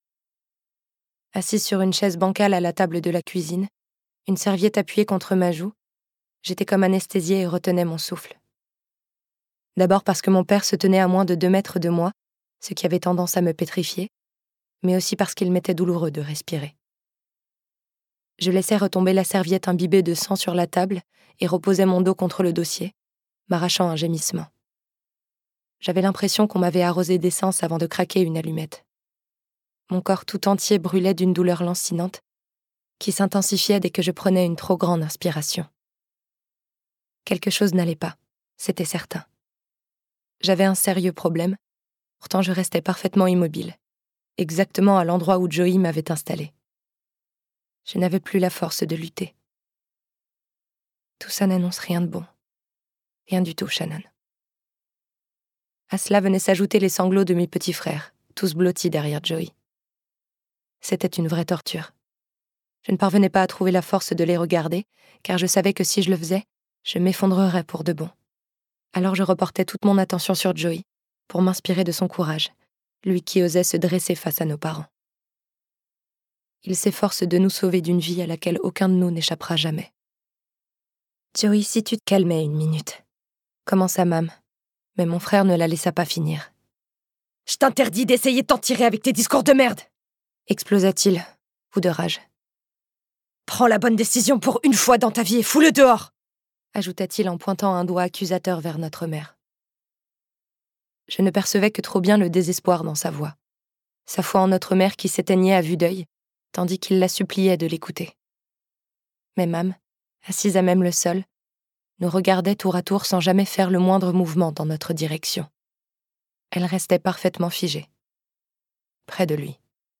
Expérience de lecture
Télécharger le fichier Extrait MP3